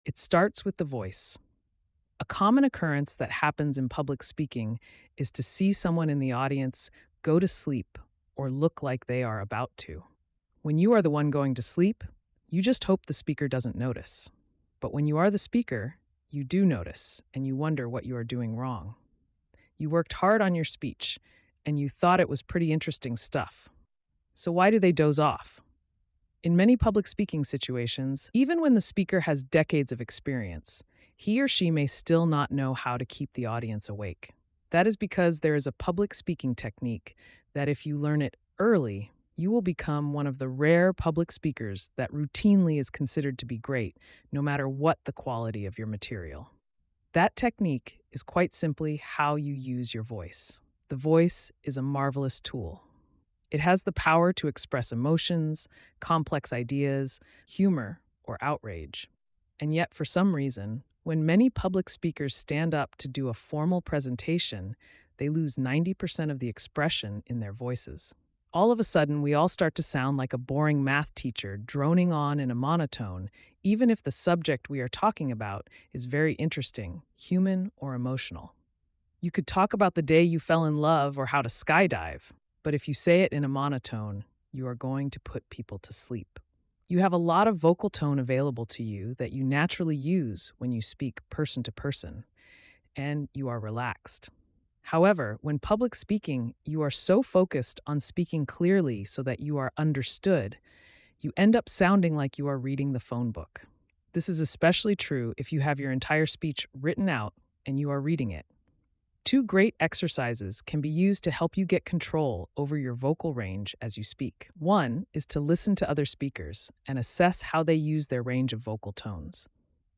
Read a Sample $3.75 Get the Audio Book You will be directed to Paypal to complete the purchase and then to BookFunnel for securely downloading your Audio Book.